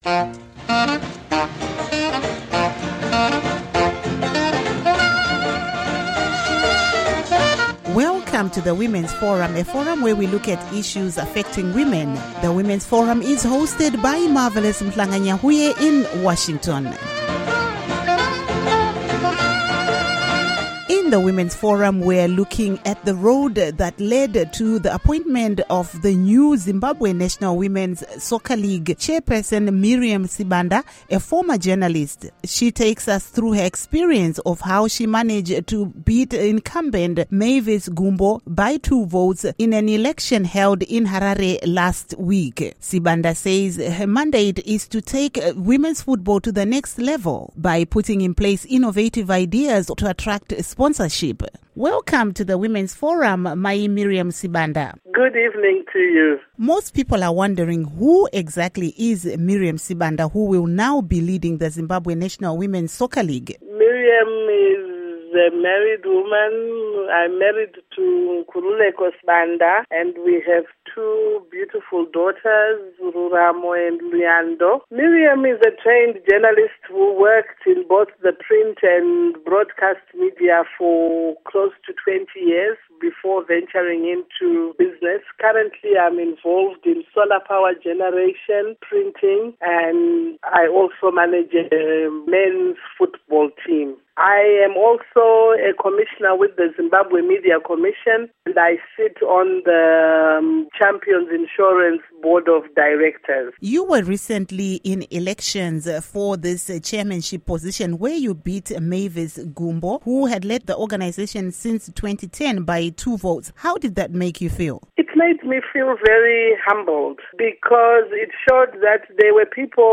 Women's Forum: Interview